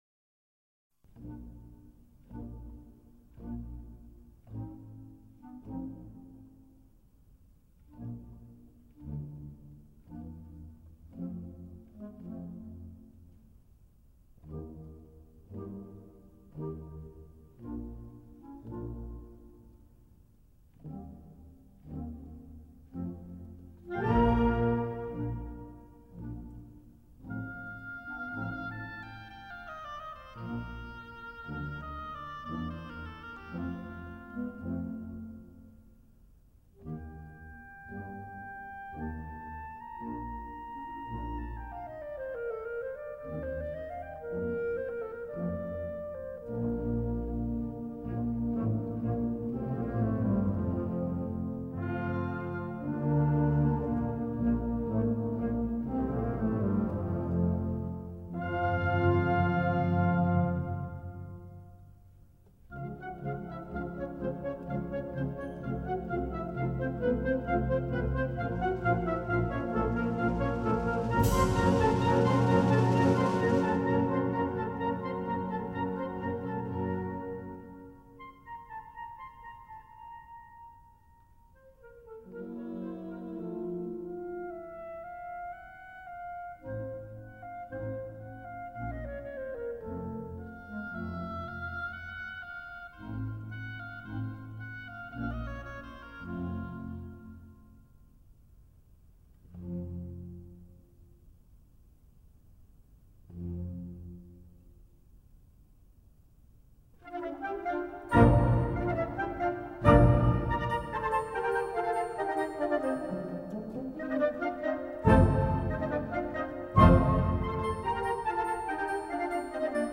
Gattung: Symphonie
Besetzung: Blasorchester